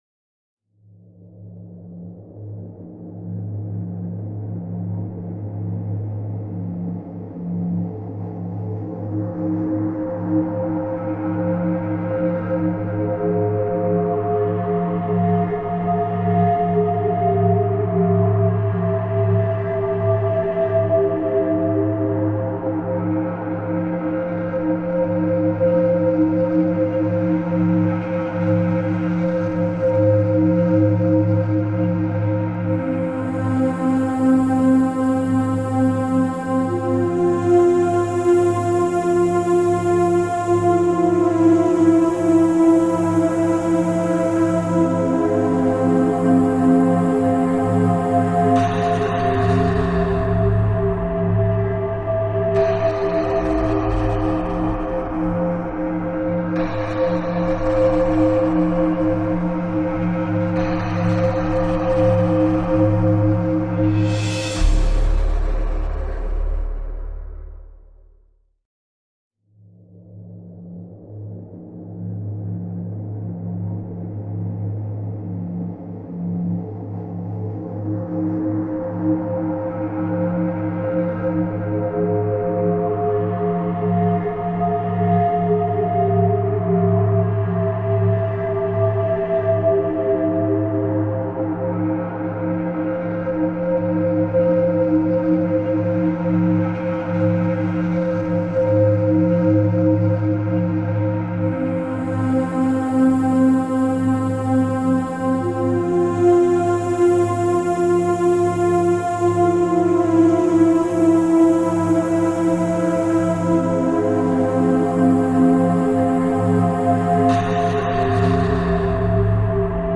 KEEkfJyT7QW_Música-instrumental-de-miedo-1.m4a